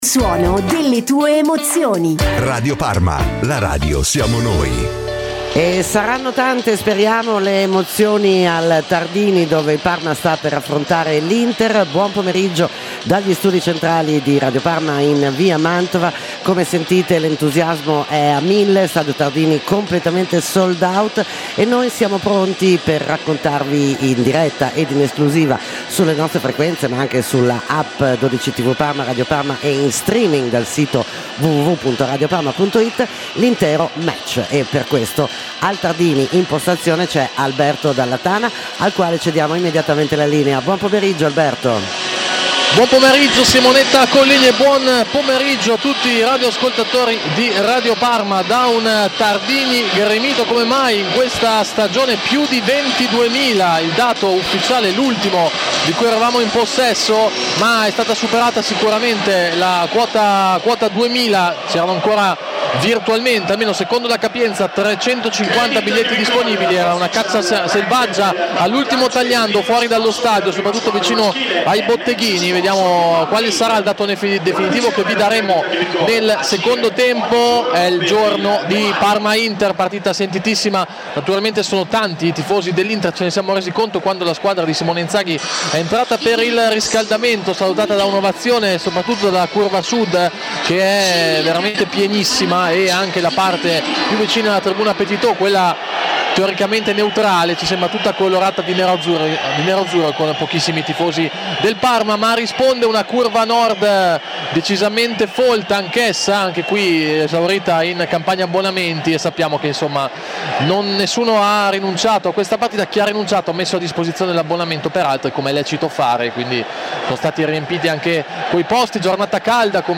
Un match attesissimo, Tardini completamente sold out. Radiocronaca